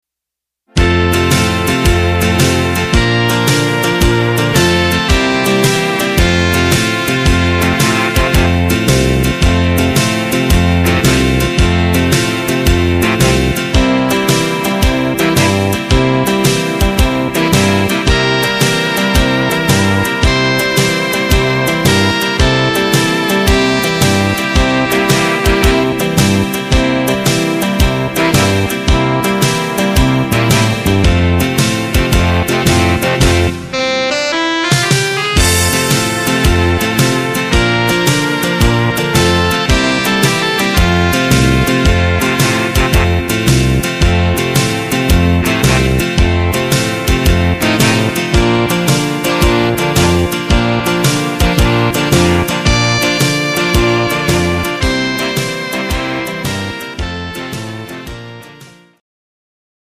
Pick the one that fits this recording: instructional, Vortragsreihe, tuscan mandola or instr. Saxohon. instr. Saxohon